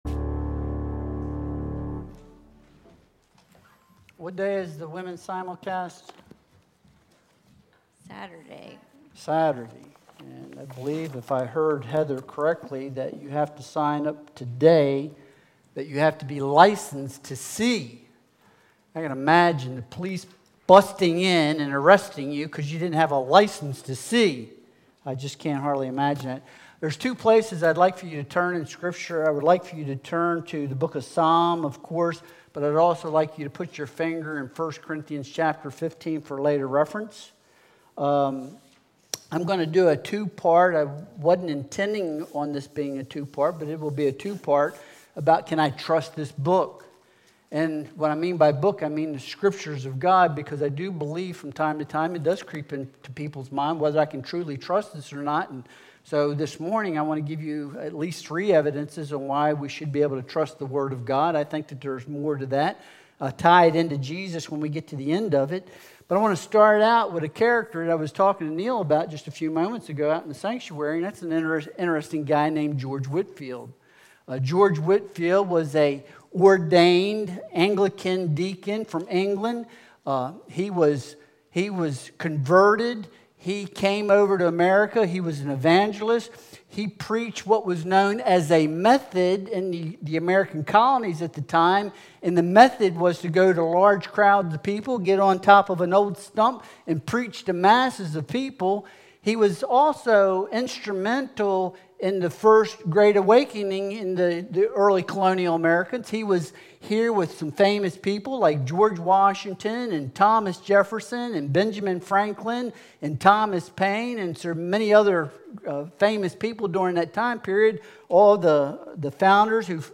Psalm 119.160 Service Type: Sunday Worship Service Download Files Bulletin « From Broken To Blessed Can I Trust This Book?